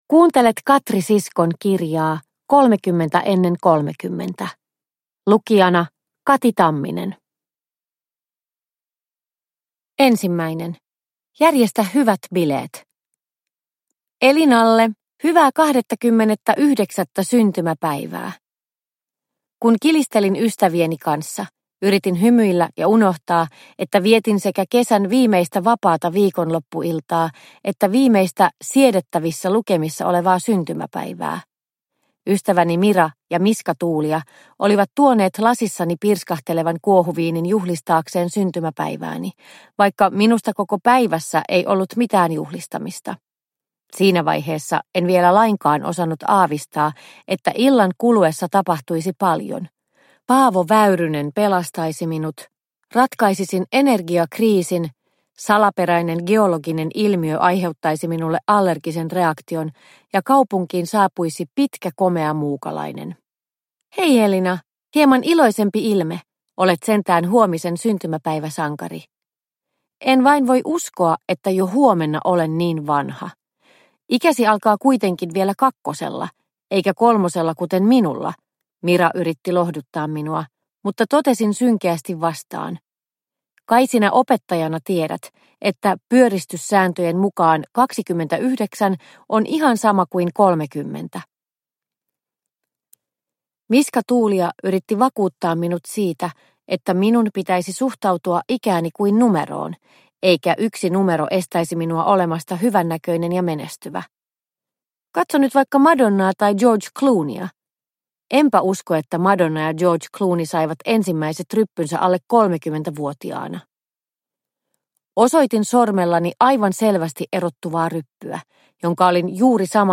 30 ennen 30 – Ljudbok – Laddas ner